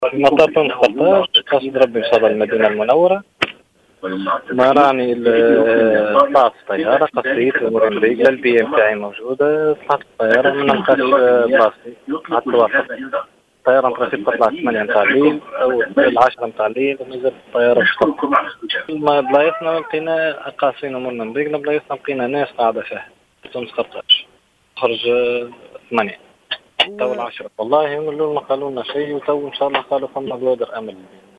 وقالوا في تصريحات لـ"الجوهرة أف أم" إنه كان من المقرر أن تقلع الطائرة على الساعة الثامنة مساء، لكنهم مازالوا إلى حدّ الآن عالقين في انتظار إيجاد حلول.